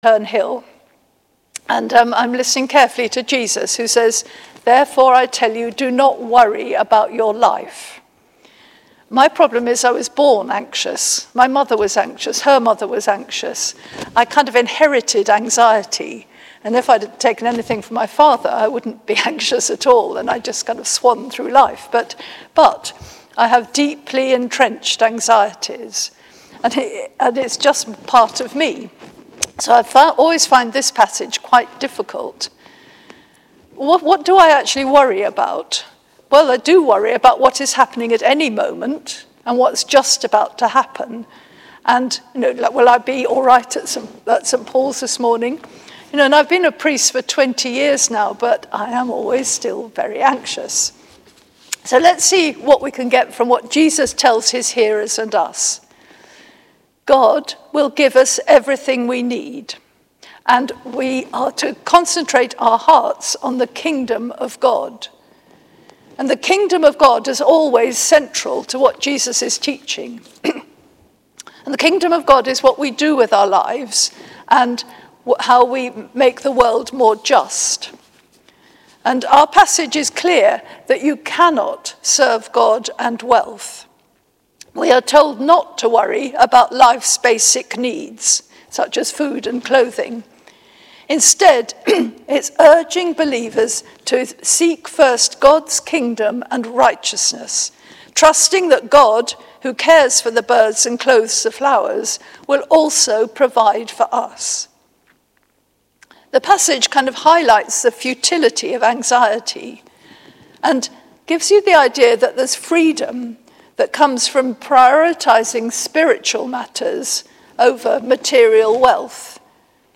Listen to our 11.15am sermon here: